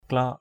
/klɔk/ 1.